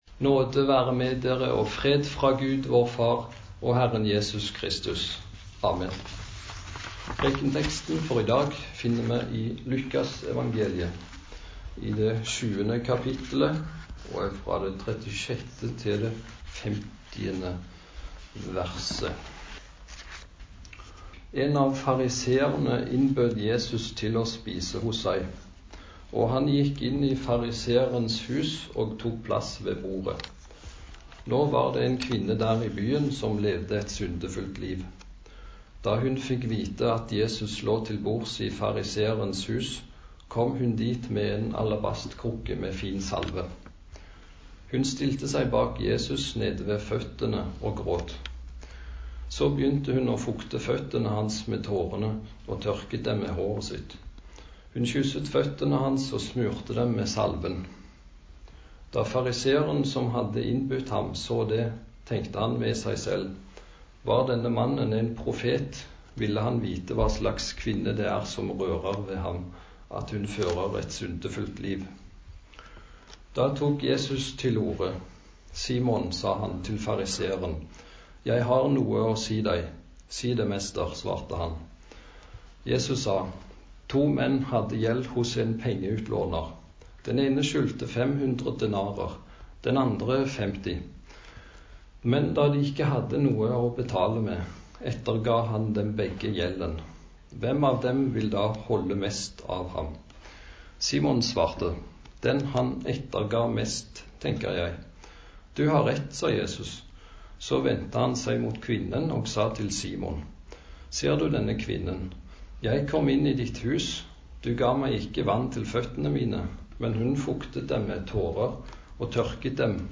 Preken på 2. søndag i faste